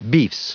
Prononciation du mot beefs en anglais (fichier audio)
Prononciation du mot : beefs